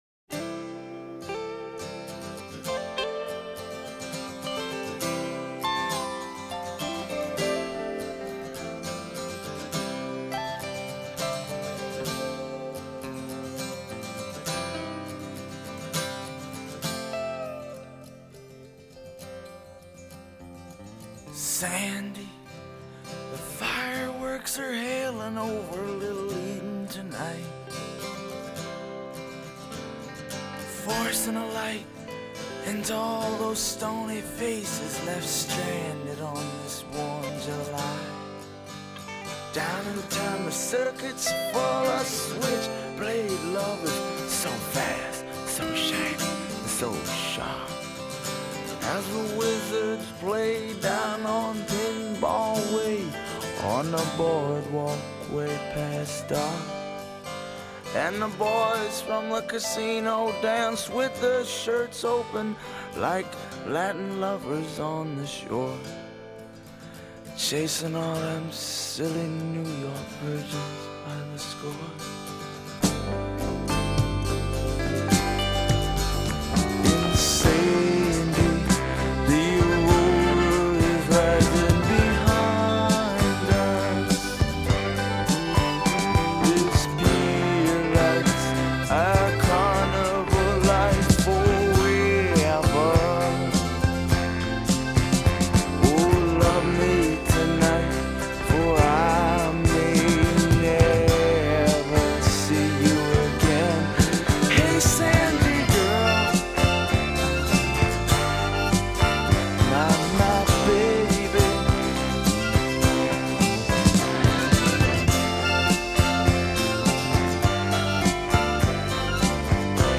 ” is a gorgeous ballad that follows